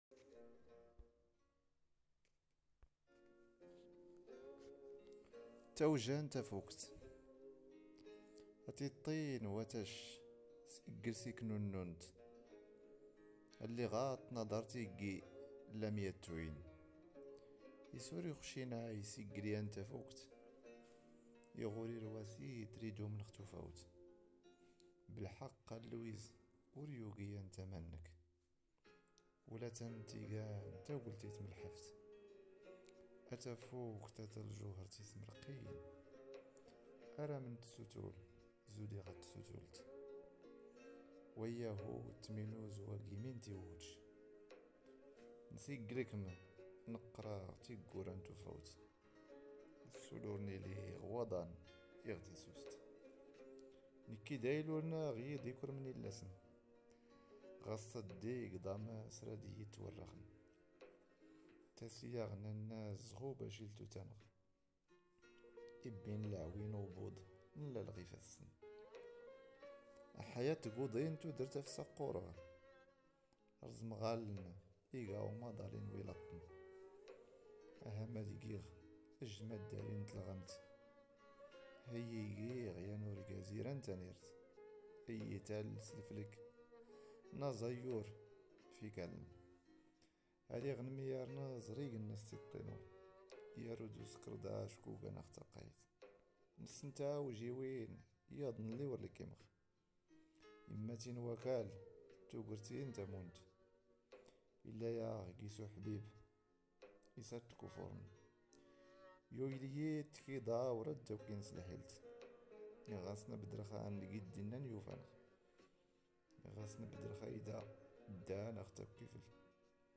تامديازت/شعر